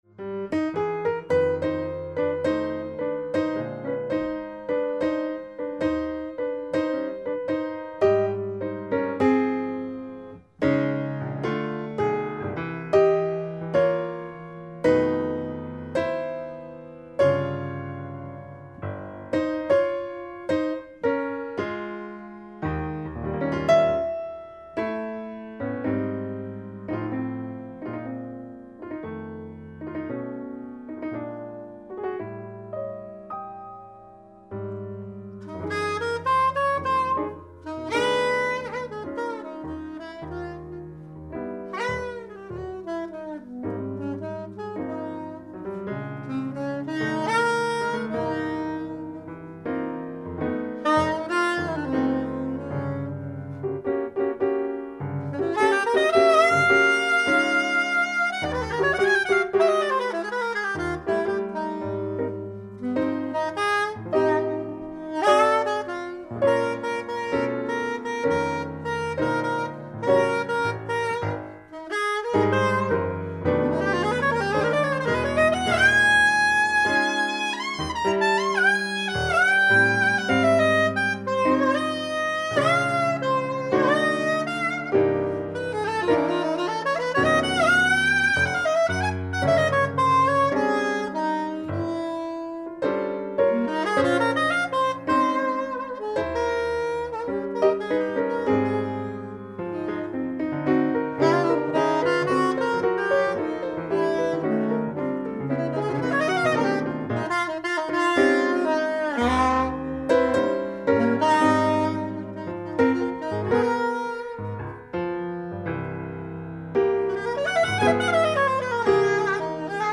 (Soprano sax. Passage d'impro et thème final)